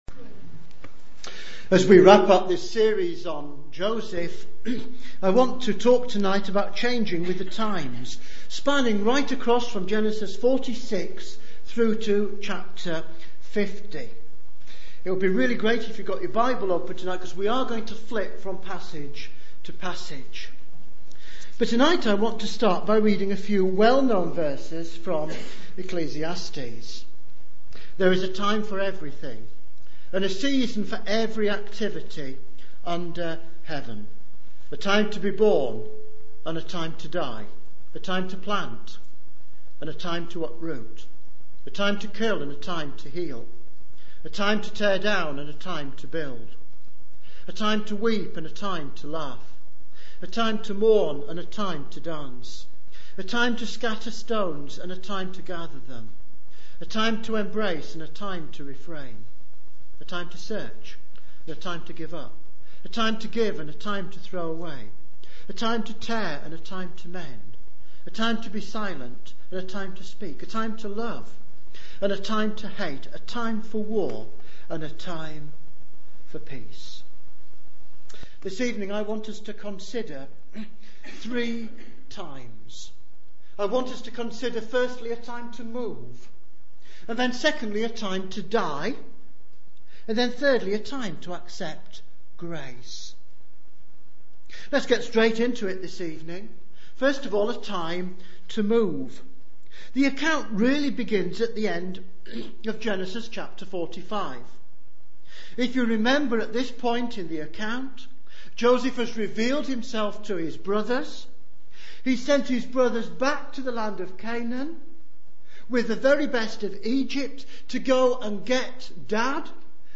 A series of sermons on the life of Joseph preached at Emmanuel Church, Northwich on Sunday evenings starting in June 2009.